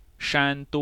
IPA[ʂán.tʊ́ŋ]
Zh-Shandong.ogg